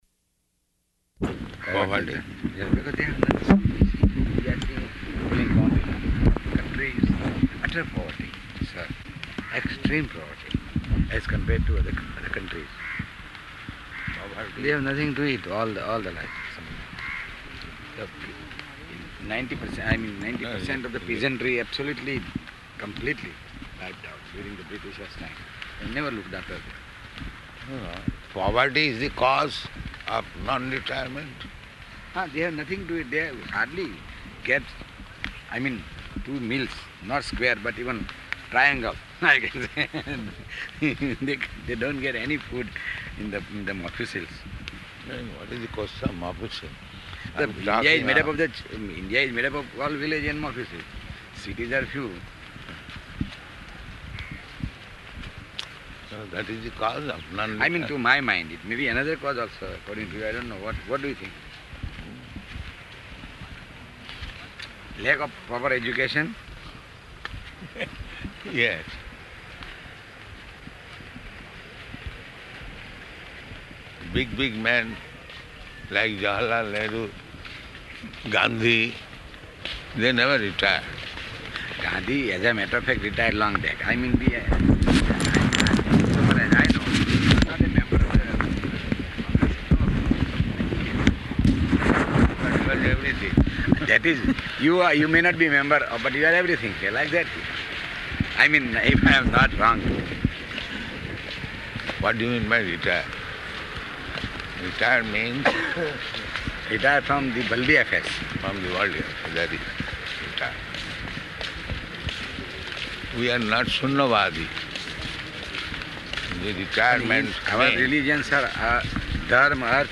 -- Type: Walk Dated: November 17th 1975 Location: Bombay Audio file